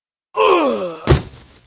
男死亡倒地2.mp3